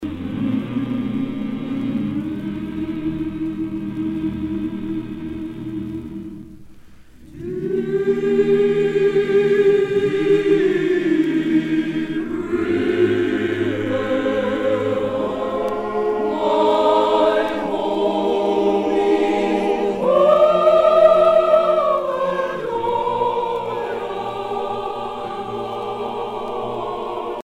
Spirituals